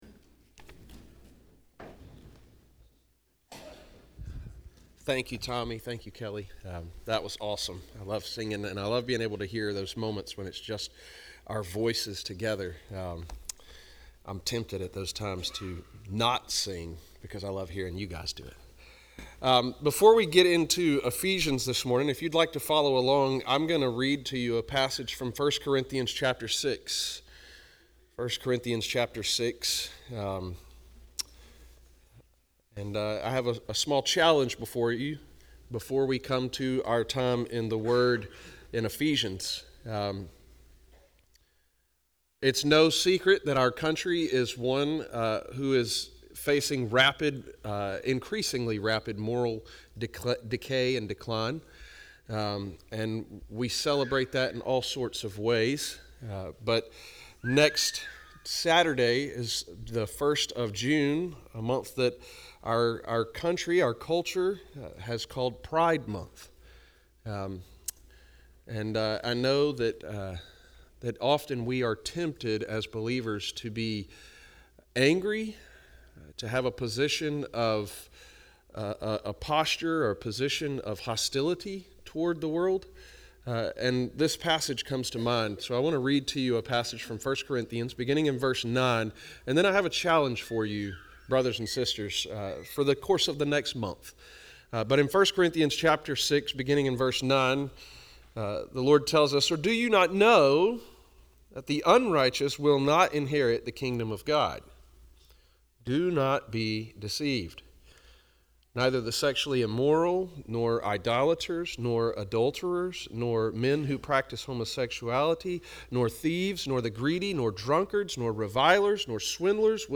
** The sermon starts at the 8:30 minute mark.